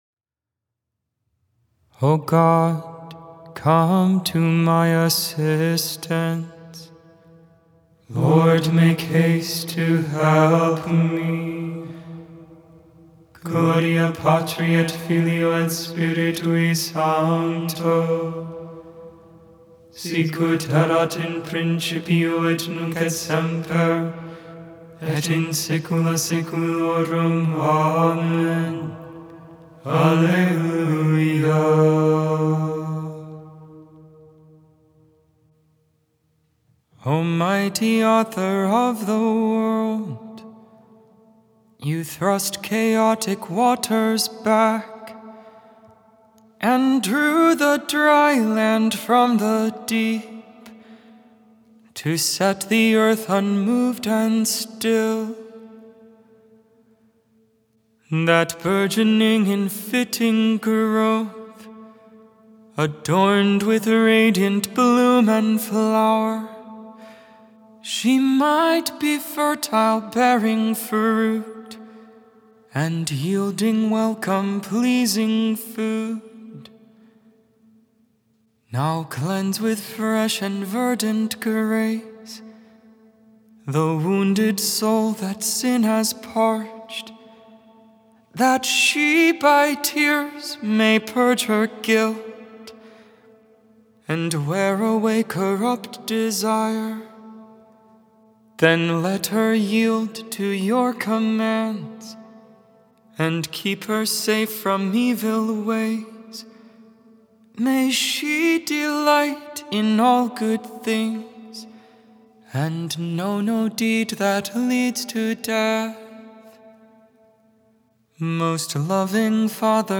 1.28.25 Vespers, Tuesday Evening Prayer of the Liturgy of the Hours